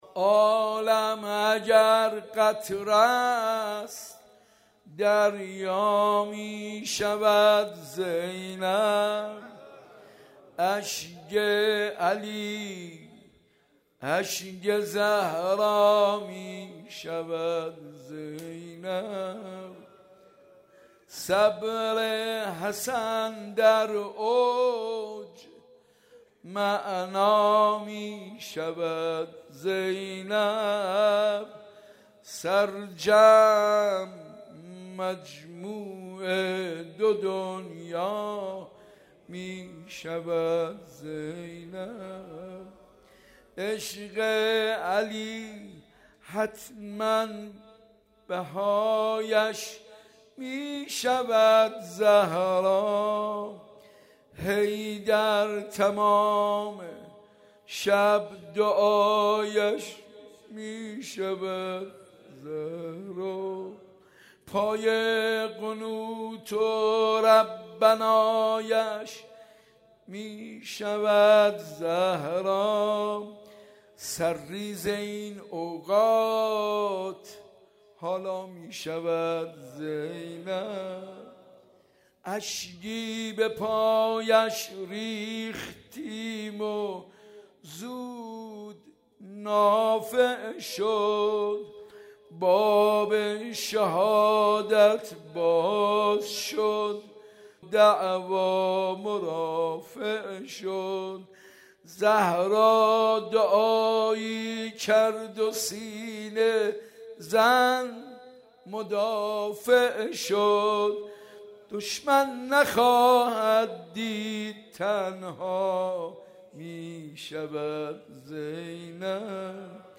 حاج منصور ارضی/مراسم هفتگی زیارت عاشور/روضه شهادت حضرت زینب(س)